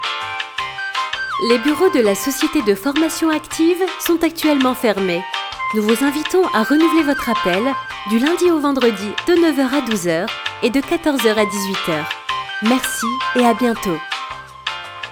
Une voix, plusieurs styles
Une voix douce, posée, rassurante, souriante, dynamique, punchy
Je dispose donc d’un studio de post-production à l’acoustique étudiée et parfaitement insonorisé.
Repondeur-1.mp3